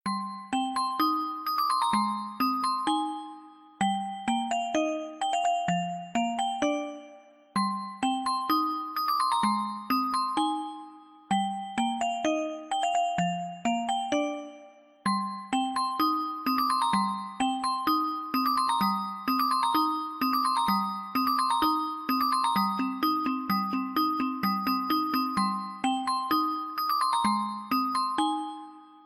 Kategori Marimba Remix